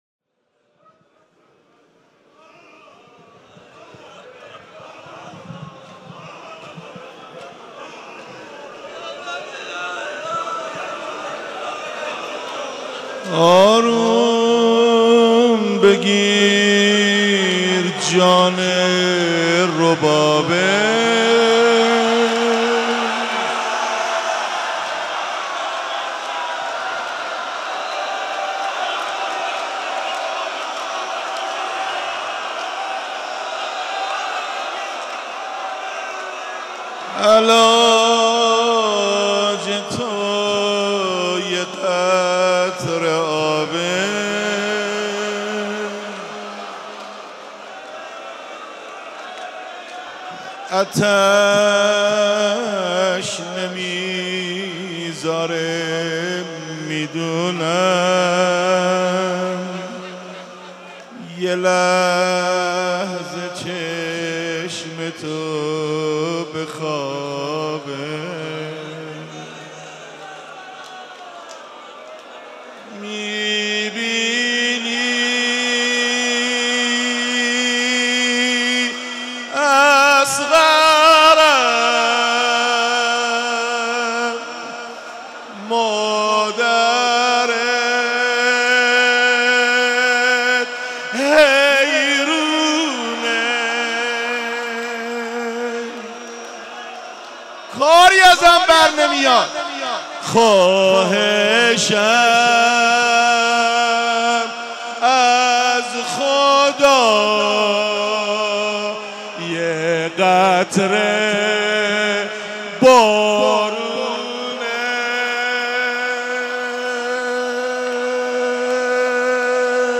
شب هفتم محرم95/مسجد حضرت امیر (ع)